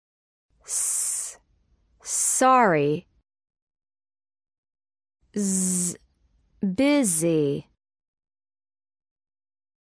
PRONUNCIATION: Saying the letter s
/s/ sorry                                                   /z/ busy